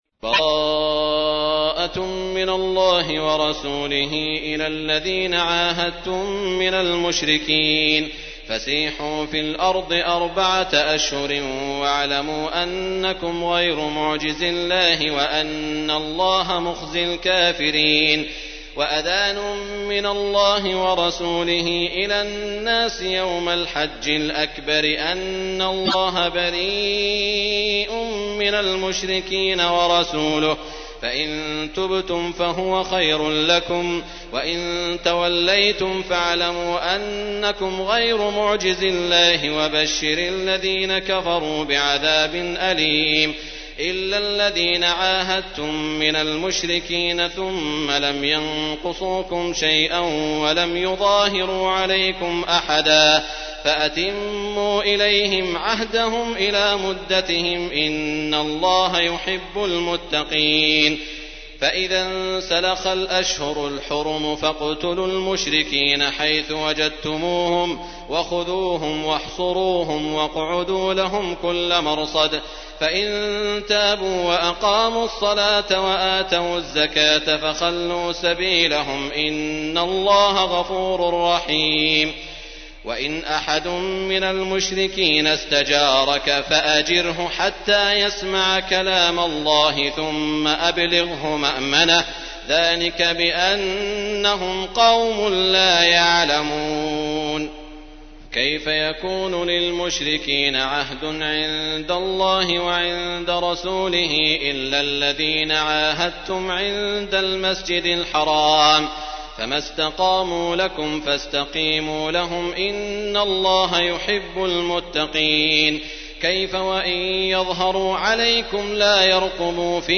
تحميل : 9. سورة التوبة / القارئ سعود الشريم / القرآن الكريم / موقع يا حسين